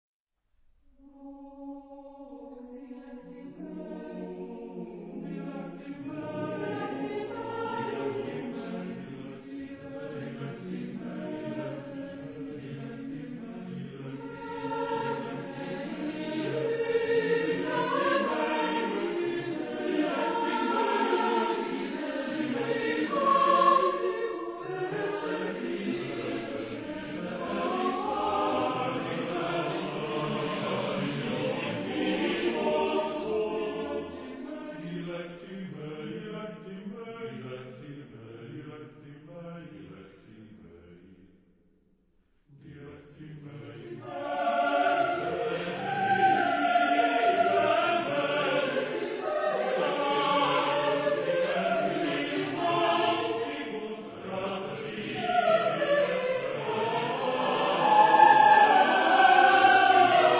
Genre-Style-Form: Sacred ; Motet
Mood of the piece: fast ; suave ; light ; broad ; andante
Type of Choir: SATBarB  (5 mixed voices )
Tonality: various